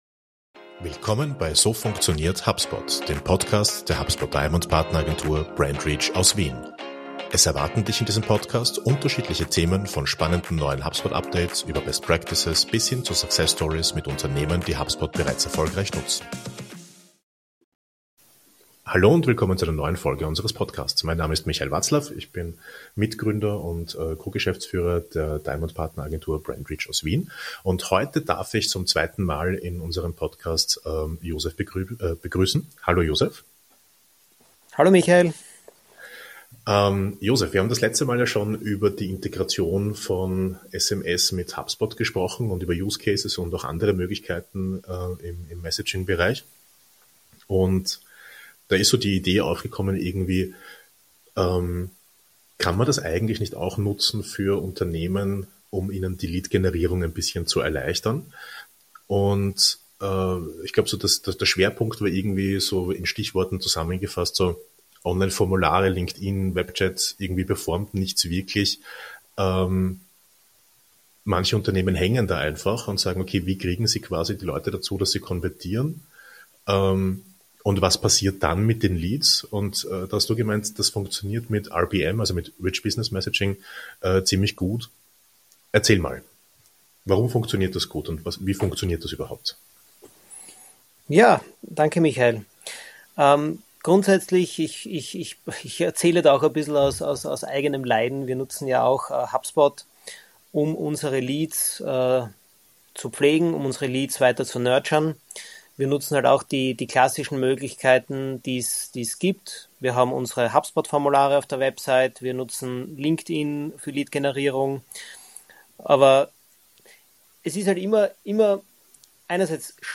Dabei geht es um praktische Einstiegspunkte wie QR-Codes, Click-to-Message Ads und den Einsatz von KI, um Beratung und Inspiration zu automatisieren. Ein spannendes Gespräch über die Verbindung von Messaging, Marketing und CRM – und warum Chats die neuen Formulare sind.